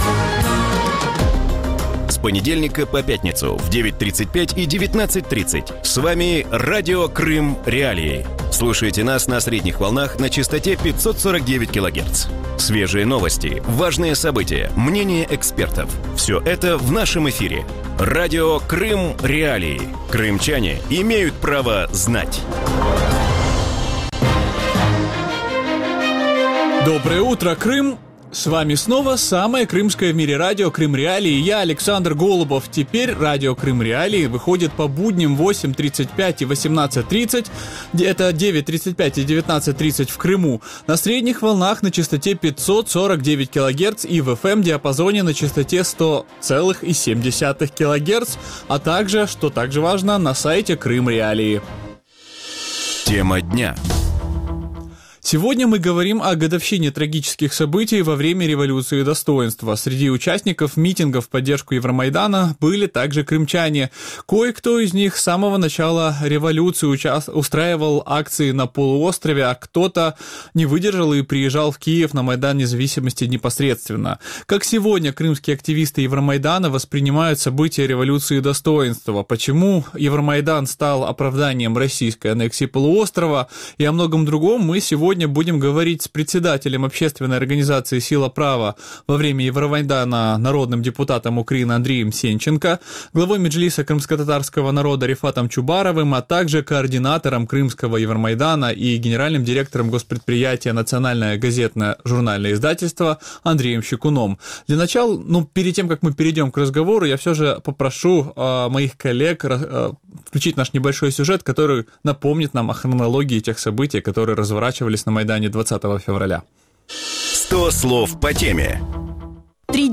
Утром в эфире Радио Крым.Реалии говорят о годовщине трагических событий во время Революции Достоинства.